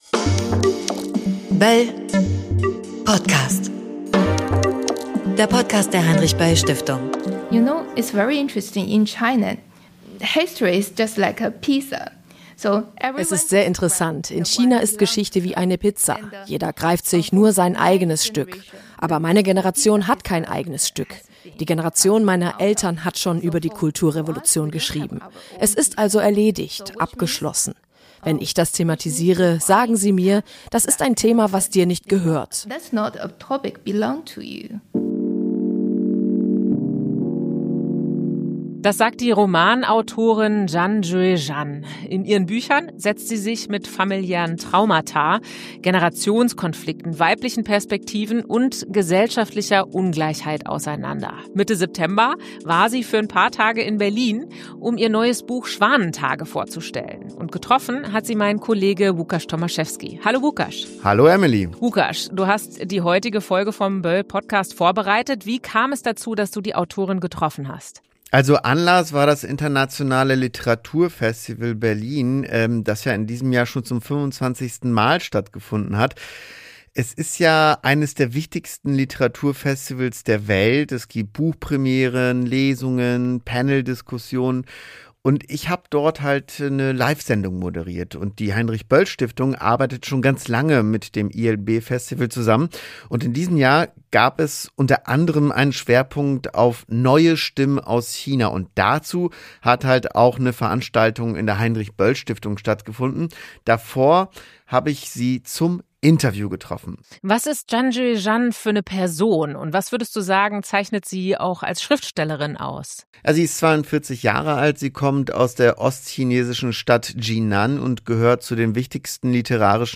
Und wie gehen junge Chines*innen mit der Vergangenheit um? Ein Gespräch mit der gefeierten Autorin Zhang Yueran.